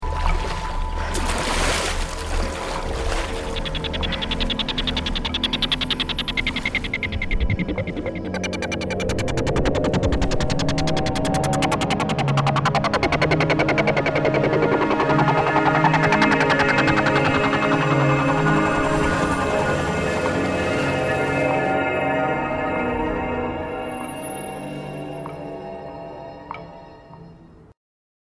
Llegada a un planeta desconocido: un escenario lleno de incertidumbre y desconcierto, donde las leyes de la física parecen transformarse. Sin embargo, en medio de la confusión, emerge una sensación de posibilidad, generando intriga y tensión, todo con un matiz intergaláctico que invita a lo desconocido.